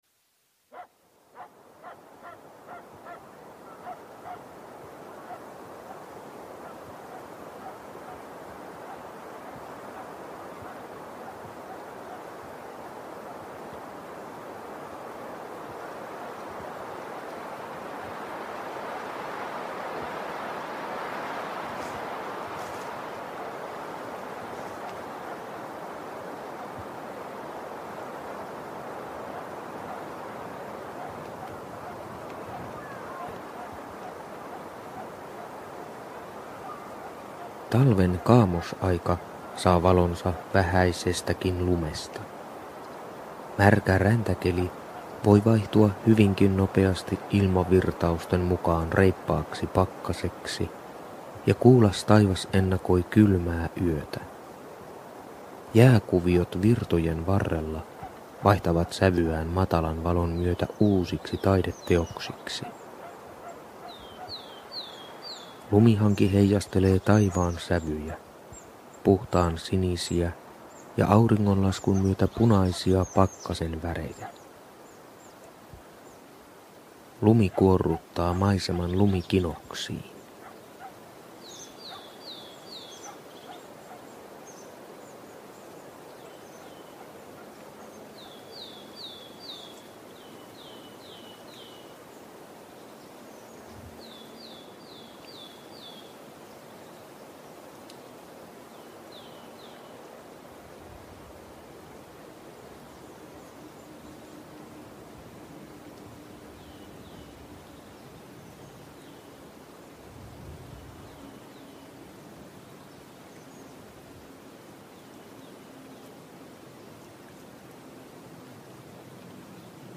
Äänitarina/maisema vuodenkierrosta.